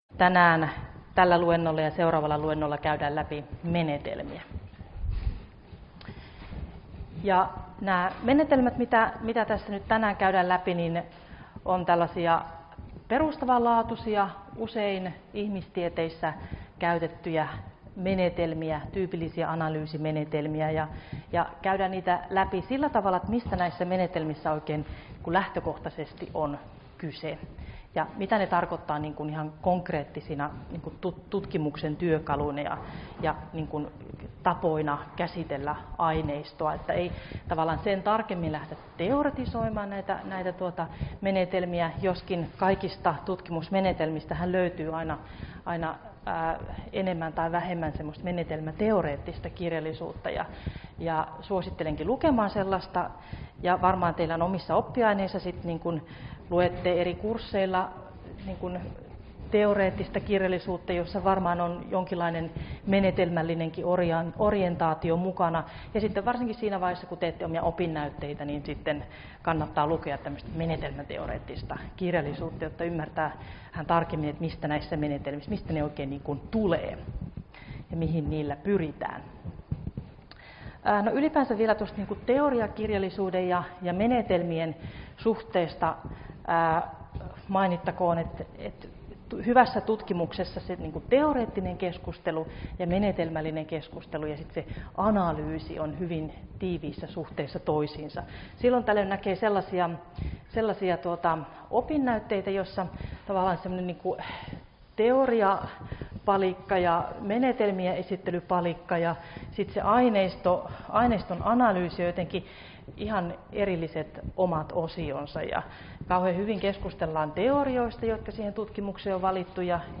Luento 8 - Aineiston analyysimenetelmiä 1 — Moniviestin